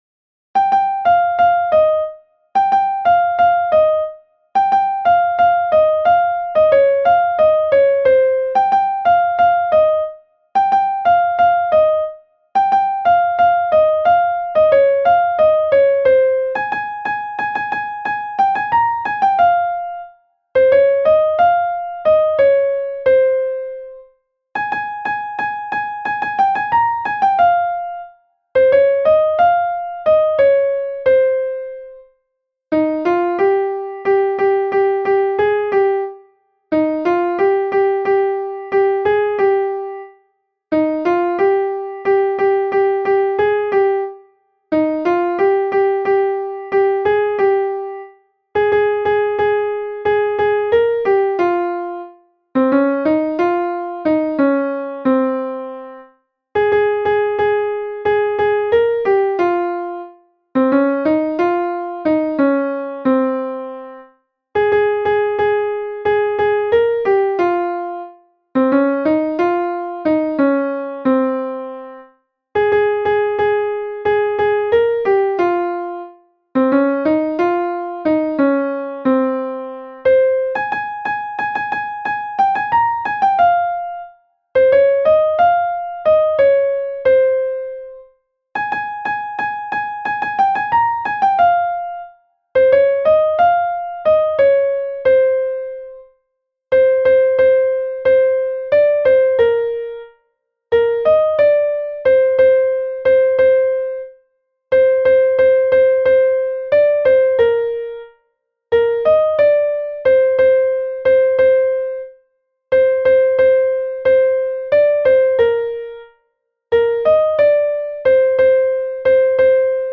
نت کیبورد آهنگ
فایل صوتی قطعه که توسط نرم افزار اجرا شده